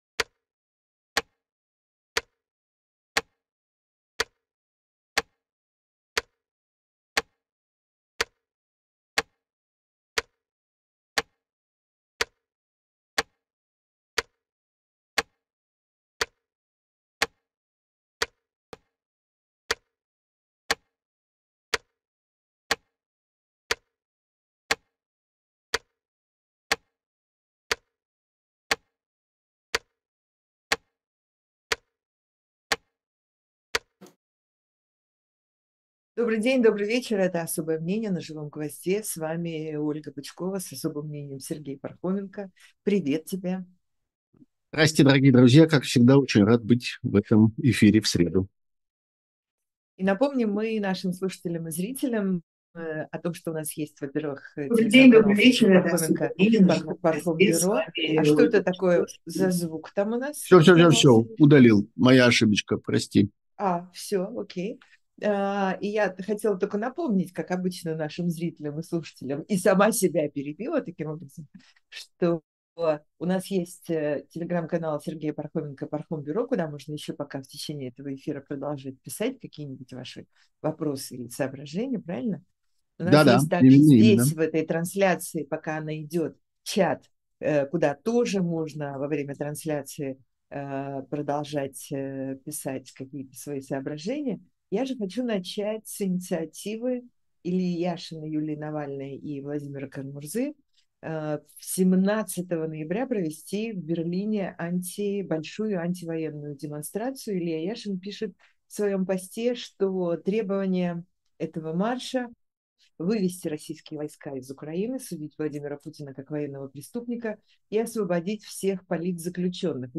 Эфир ведёт Ольга Бычкова